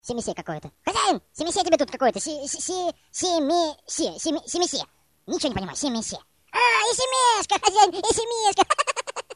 Категория: SMS рингтоны | Теги: SMS рингтоны, Super